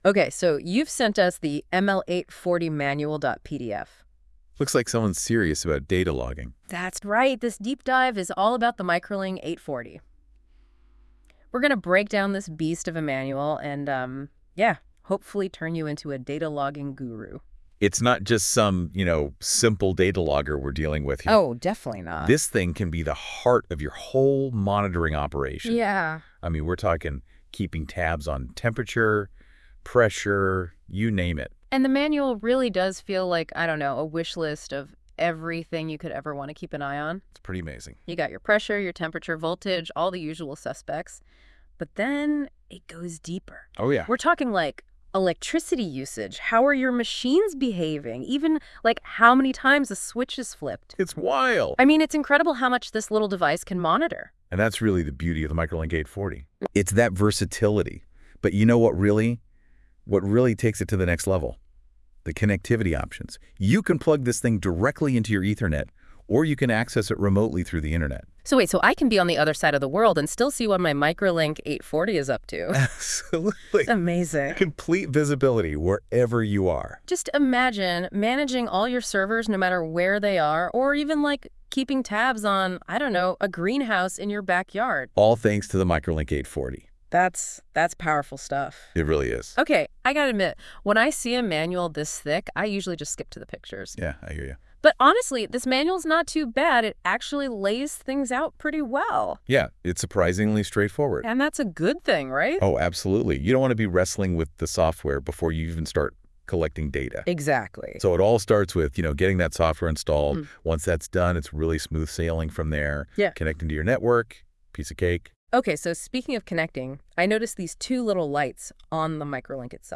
Listen to a conversation about the Microlink 840 and its manual...